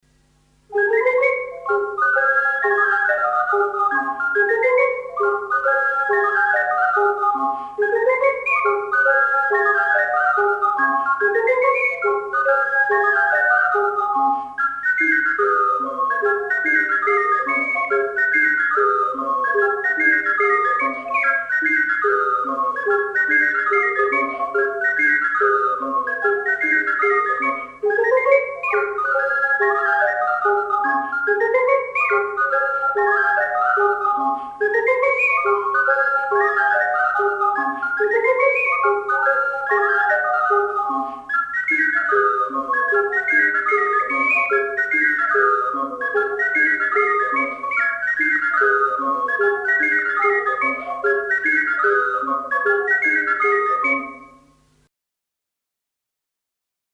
Balli popolari emiliani in .mp3
in incisione multipla con 5 ocarine